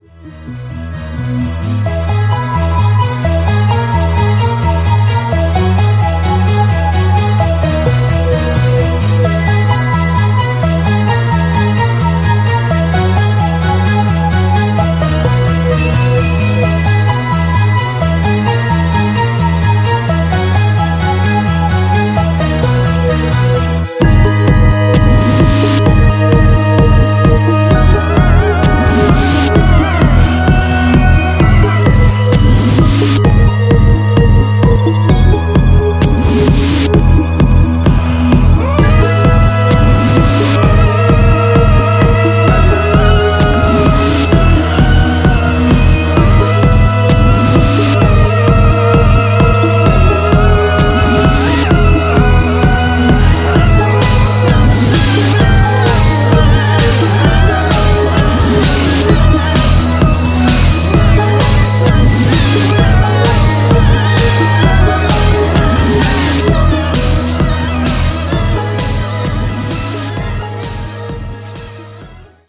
Alternative, Avantgarde, Industrial, Metal